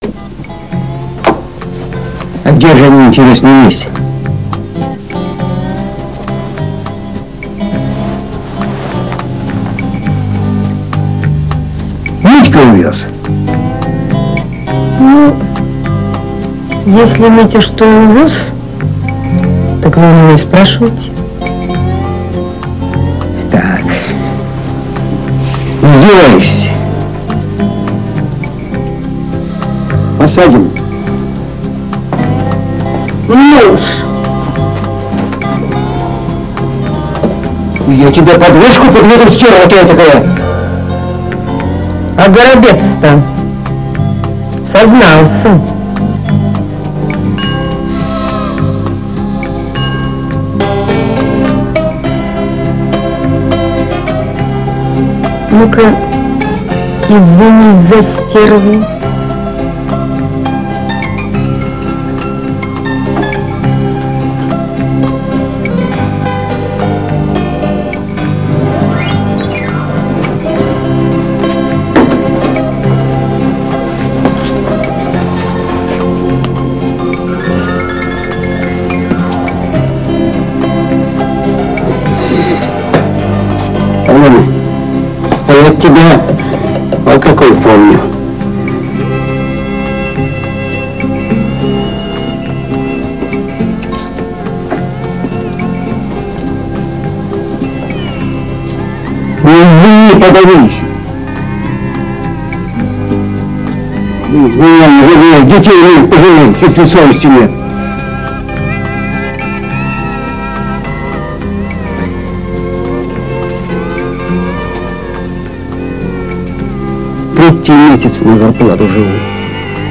Эта мелодия похожа на исполнение в стиле "кантри".